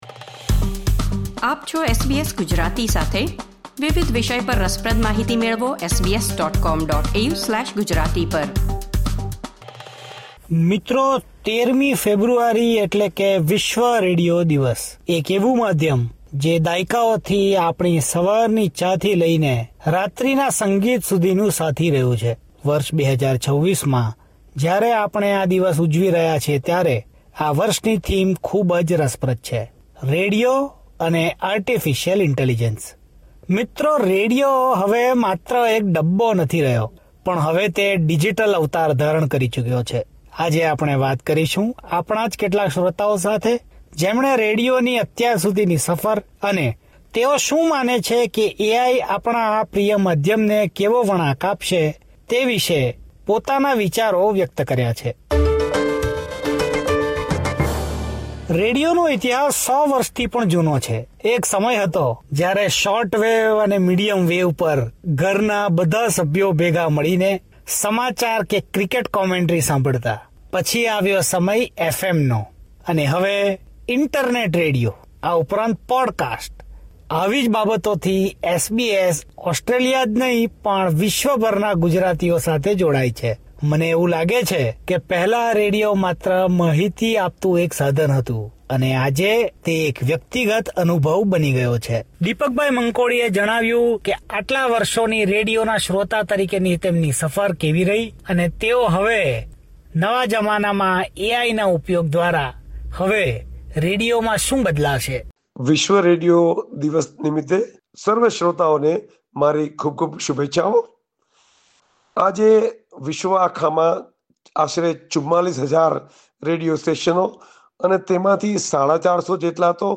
Australian radio listeners are wishing radio stations on World Radio Day